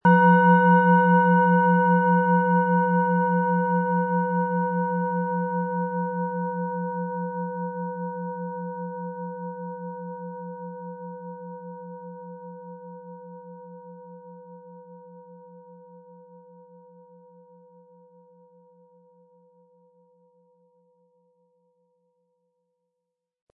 Antike Klangschale mit Wasser-Ton – Für innere Beweglichkeit, Tiefe & fließende Emotionen
Ein Klang wie ein innerer Strom
Ihr Klang erinnert an das stetige Fließen des Lebens – mal leise, mal kraftvoll, immer in Bewegung.
Spielen Sie die Schale mit dem kostenfrei beigelegten Klöppel sanft an und sie wird wohltuend erklingen.
MaterialBronze